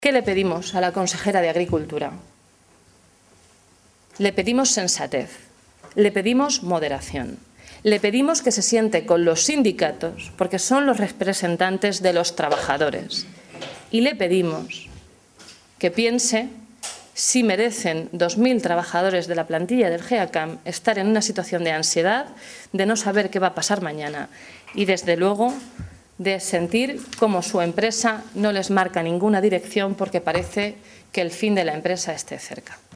Blanca Fernández, portavoz de Agricultura del Grupo Parlamentario Socialista
Cortes de audio de la rueda de prensa